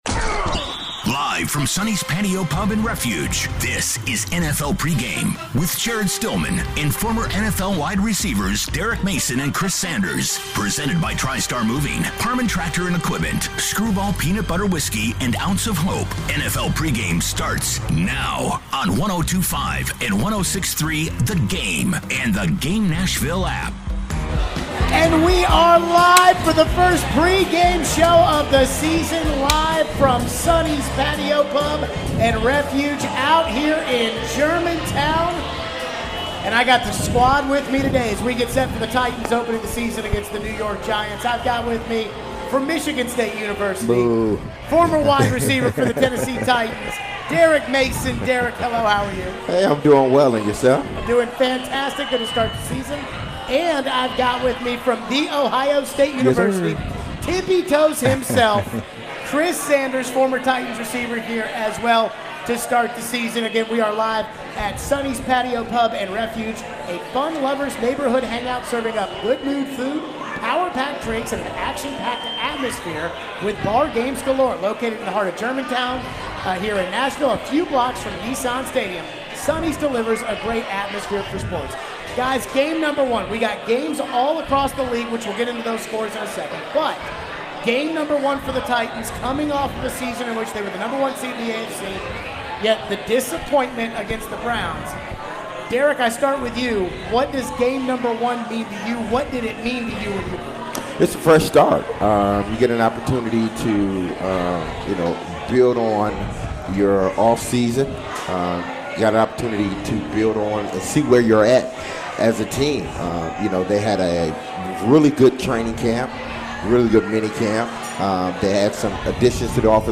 preview the Titans' week one matchup against the Giants live from Sonny's Patio Pub & Refuge in Germantown!